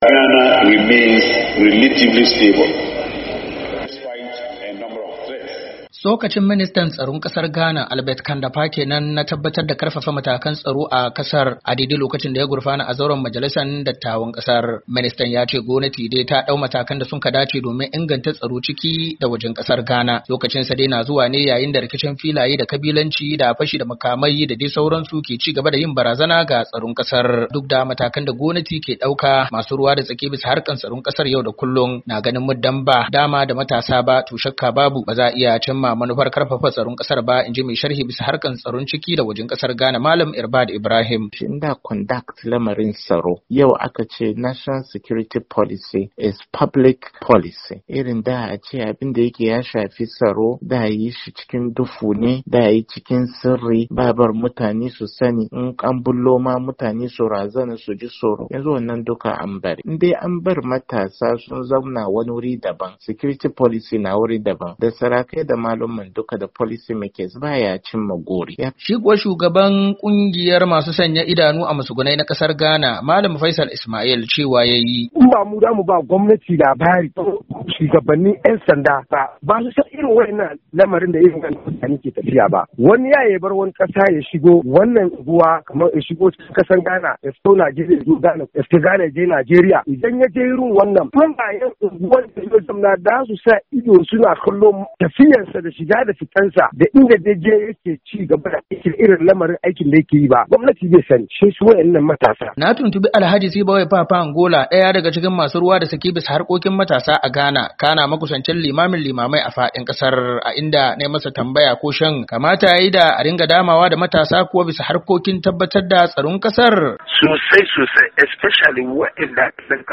Saurari rahoto cikin sauti